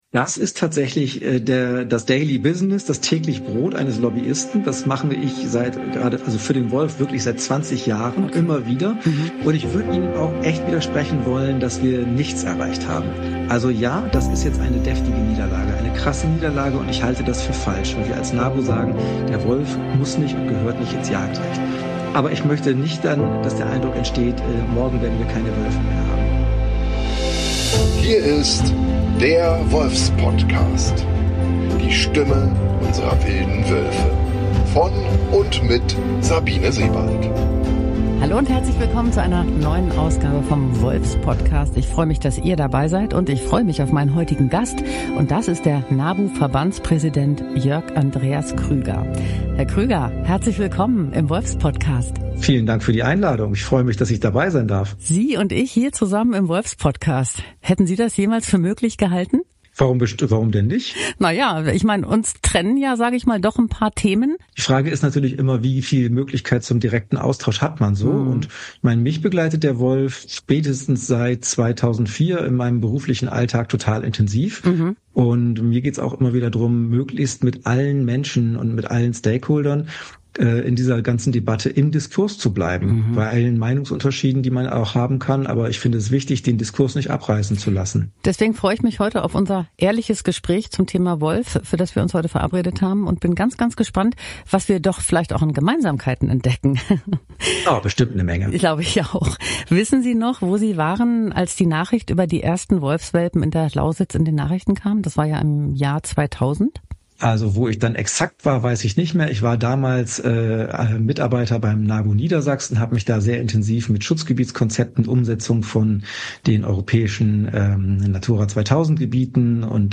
Die Kernfrage bleibt jedoch: Wie viel Haltung braucht der Naturschutz gerade jetzt in Zeiten, wo die NGOs durch die Politik unter massiv Druck gesetzt werden? Ein offenes und sehr aufschlussreiches Gespräch mit klaren Antworten und einem Versprechen.